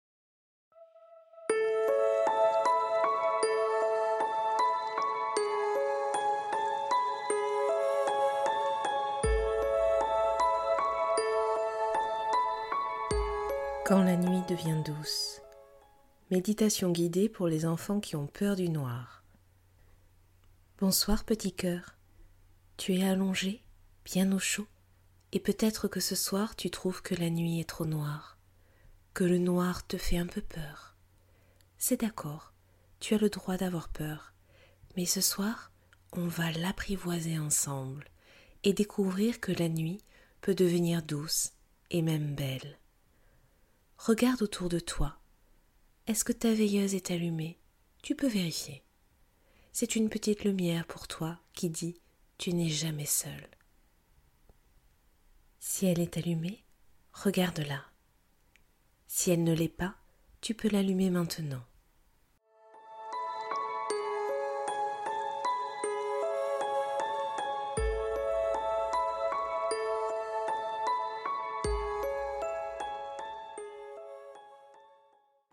Méditation guidée de Nox, peur du noir : un voyage audio apaisant qui aide les enfants à calmer leurs inquiétudes et à trouver le sommeil.
Extrait-meditation-J_ai-peur-du-noir-Nox.mp3